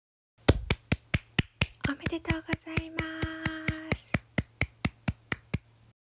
【おめでとう（拍手付き）】